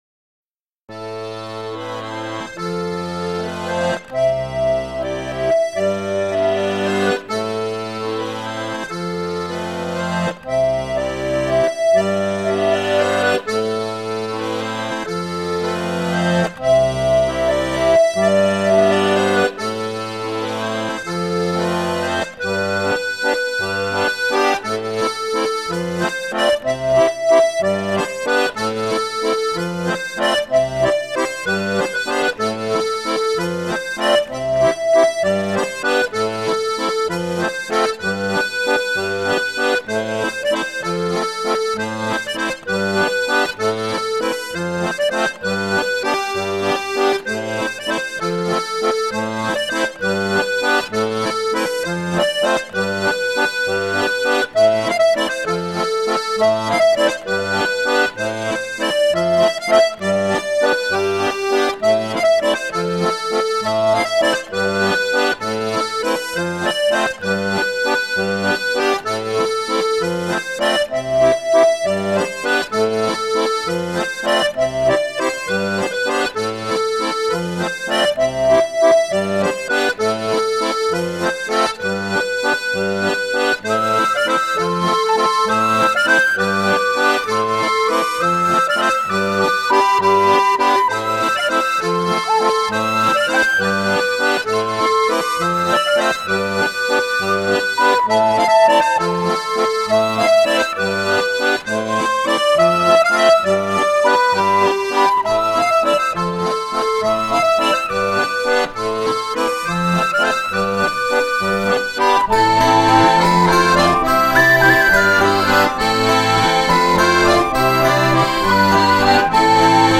Répétition du 11/11/2011 - Module 6: Trégor/Corrèze
AudioMusique de la valseMusique Ogg (4.25 MB)
lozere_valse.mp3